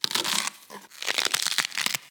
Cortar una acelga